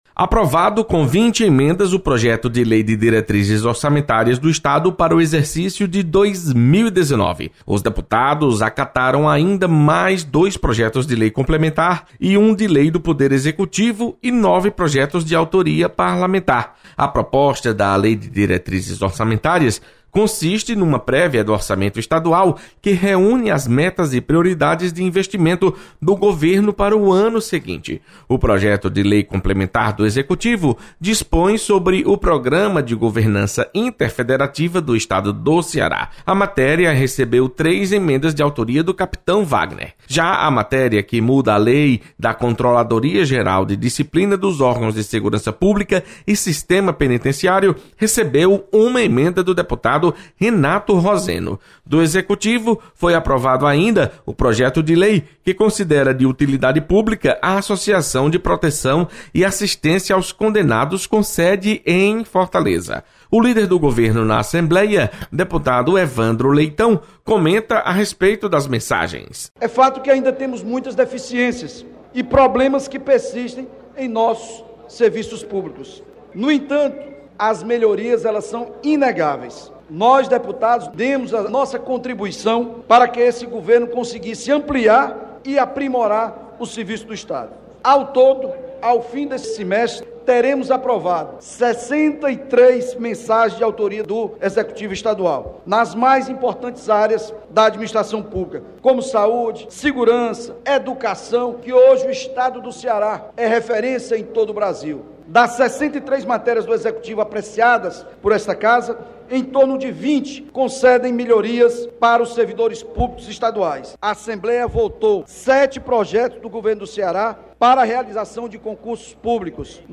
Aprovada LDO 2019. Repórter